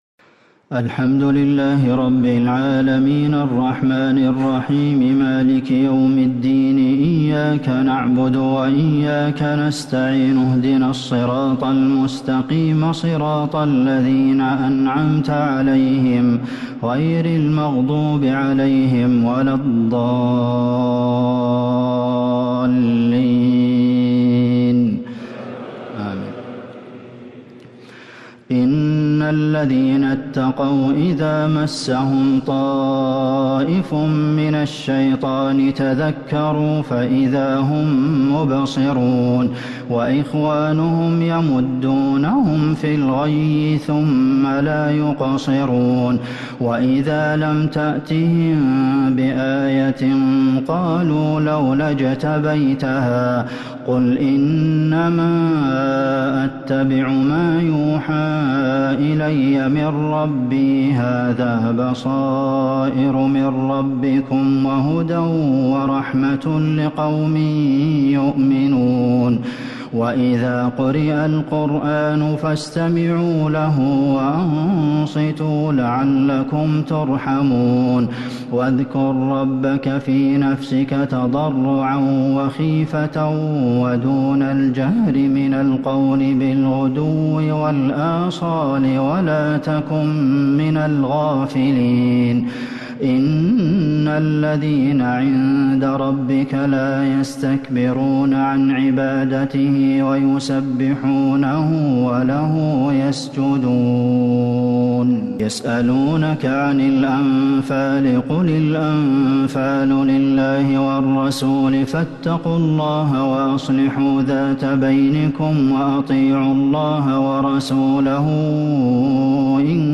تراويح ليلة 12 رمضان 1444هـ من سورتي الأعراف (201-206) و الأنفال (1-34) | taraweeh 12st niqht Surah Al-A’raf and Al-Anfal 1444H > تراويح الحرم النبوي عام 1444 🕌 > التراويح - تلاوات الحرمين